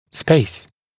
[speɪs] [спэйс] пространство, промежуток; трема, промежуток между зубами